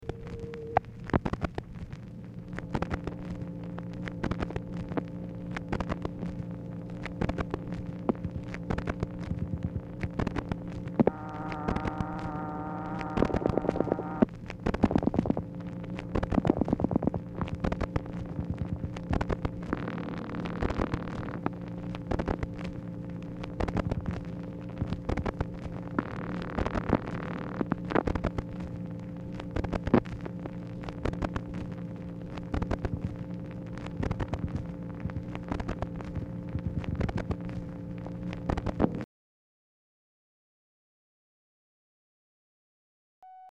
Telephone conversation
MACHINE NOISE
Dictation belt